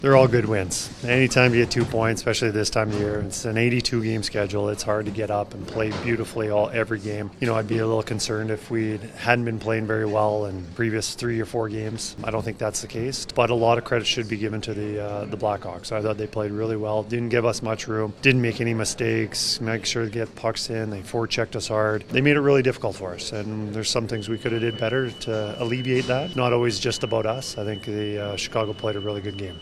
Head coach for the Oilers Kris Knoblauch says during an 82 game season, it’s hard to bring your best game night in and night out, but praised his teams resilience against the Blackhawks.